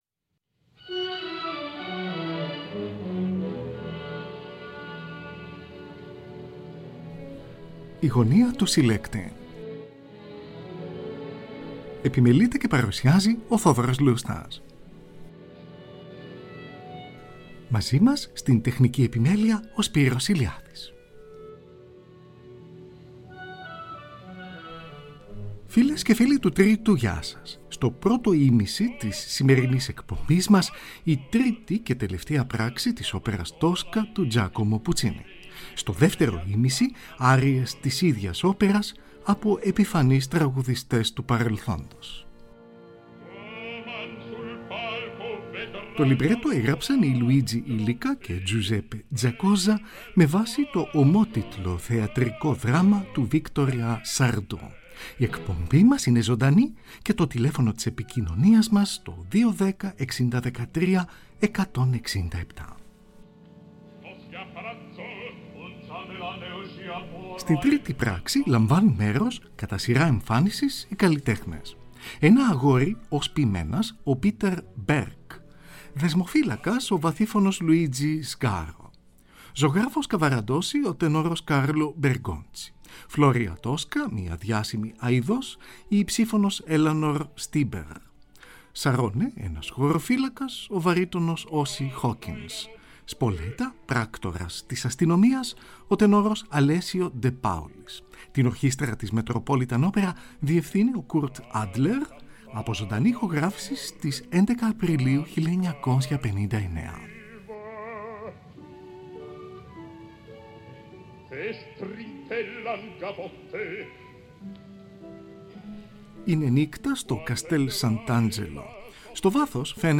Floria Tosca , μια διάσημη αοιδός , η υψίφωνος Eleanor Steber .
ο τενόρος
Τη Χορωδία και την Ορχήστρα της Metropolitan Opera διευθύνει ο Kurt Adler , από ζωντανή ηχογράφηση στις 11 Απριλίου 1959 .